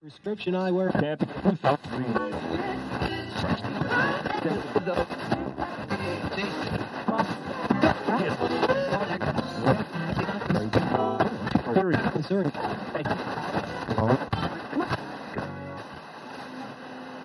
Radio Tuning, Tuning Radio, Various Stations, Mostly Static & Voices.